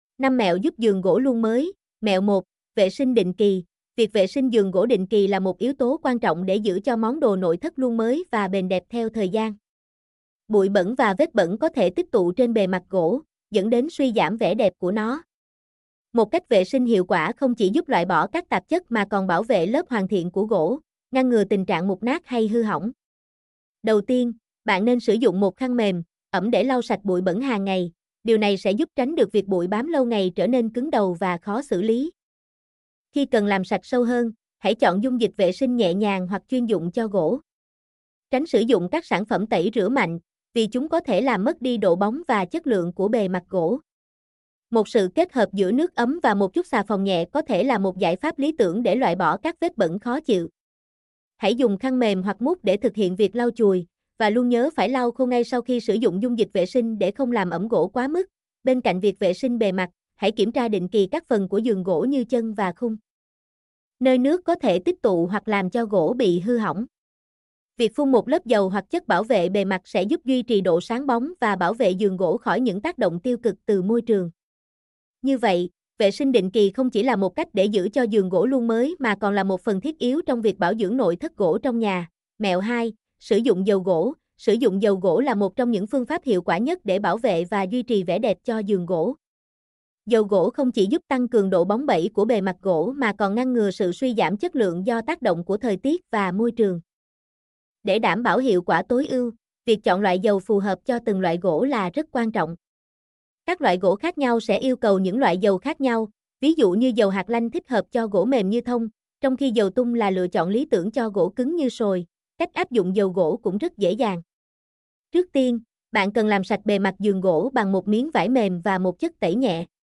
mp3-output-ttsfreedotcom-5.mp3